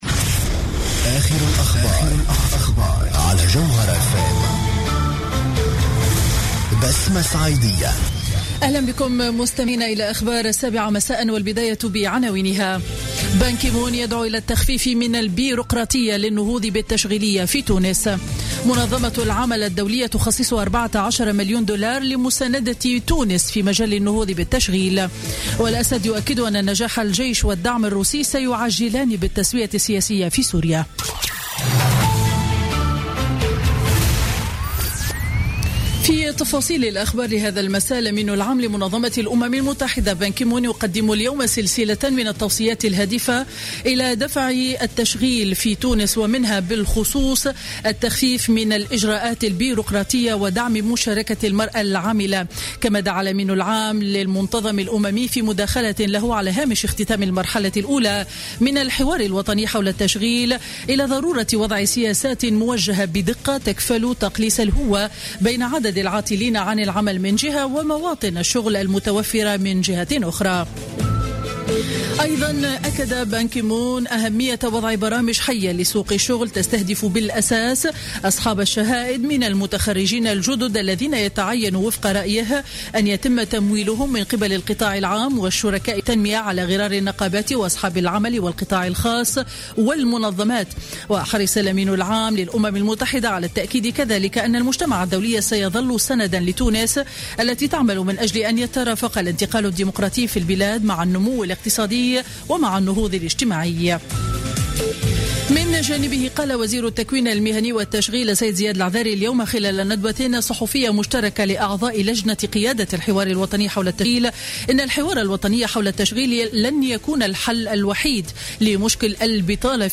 نشرة أخبار السابعة مساء ليوم الثلاثاء 29 مارس 2016